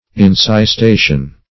Encystation \En`cys*ta"tion\, n.